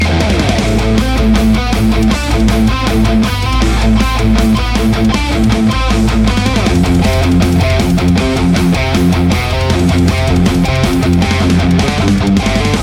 It's fully loaded for any Hi-Gain application. 100 Watts of sofisticated Rock and Metal tones, It's all about gain!
Metal Riff Mix
RAW AUDIO CLIPS ONLY, NO POST-PROCESSING EFFECTS